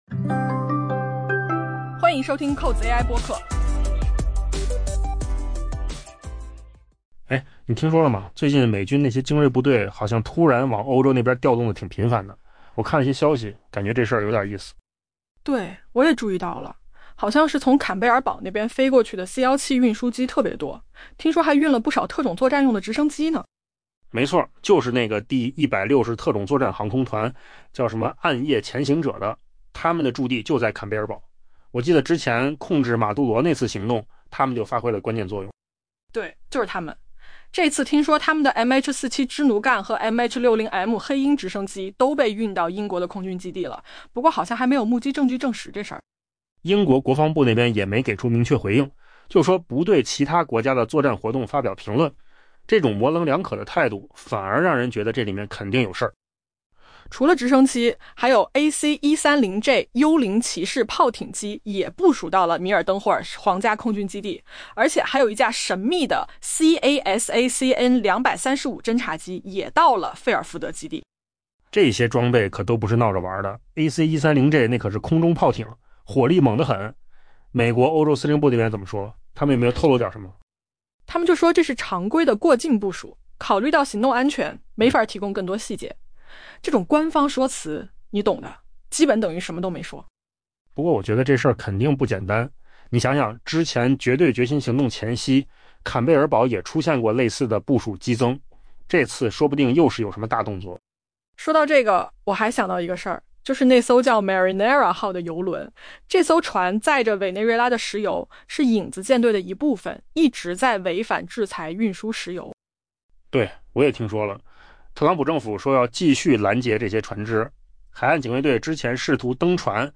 AI 播客：换个方式听新闻 下载 mp3 音频由扣子空间生成 开源追踪数据和地面观察员发现， 美军飞机正突然大举涌向欧洲 。